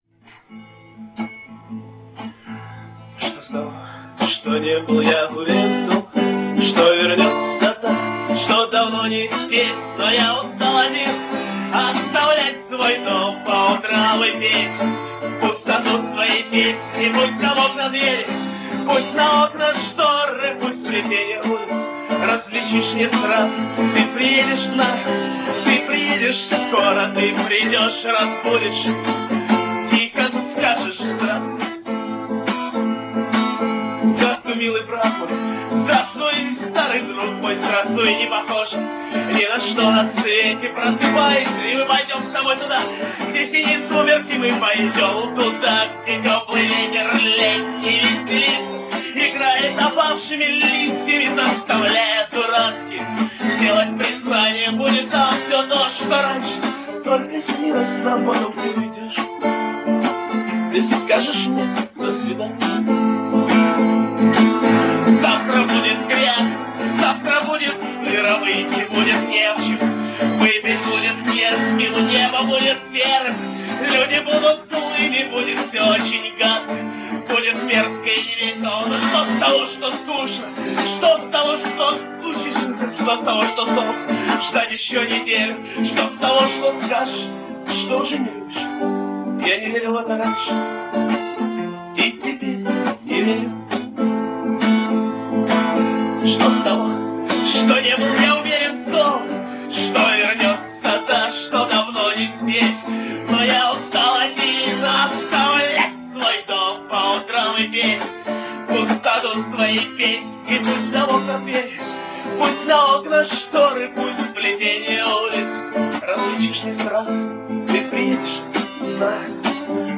Квартирник 26 октября 1999.